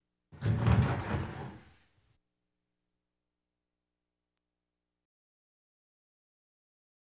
Door Wooden Slide Open Sound Effect
Download a high-quality door wooden slide open sound effect.
door-wooden-slide-open.wav